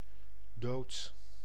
Ääntäminen
IPA: /doːt/